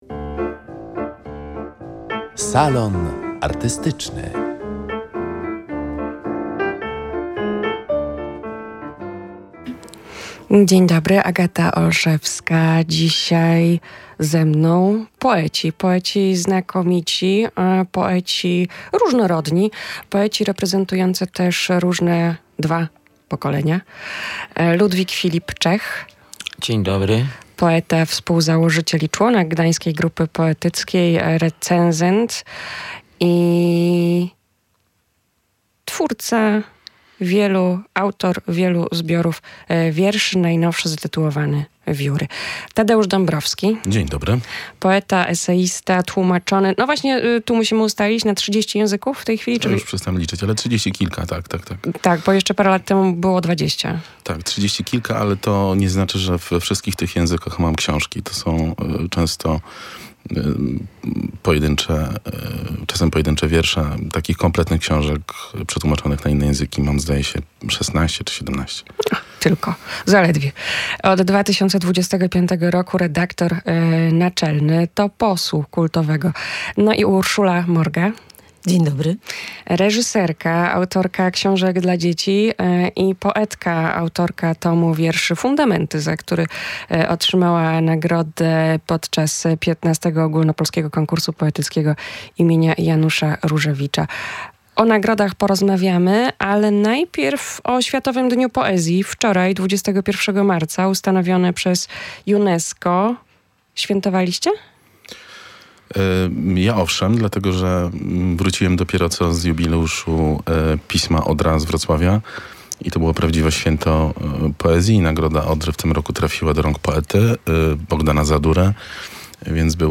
Komu potrzebny jest poeta? Debata w Salonie Artystycznym